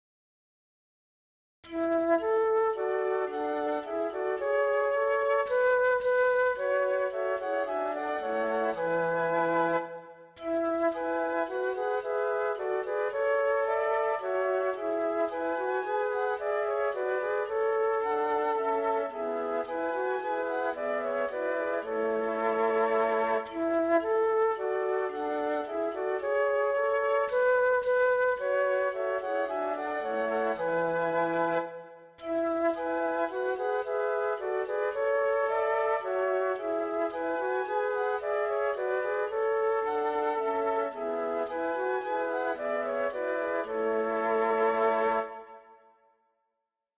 TTBB (4 voix égales d'hommes) ; Partition complète.
Type de choeur : TTBB (4 voix égales d'hommes )
Tonalité : la majeur